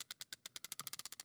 ReelRollFree.ogg